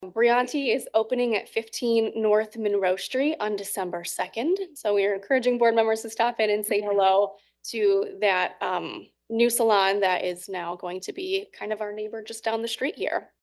during Wednesday’s Downtown Development Authority Board of Directors meeting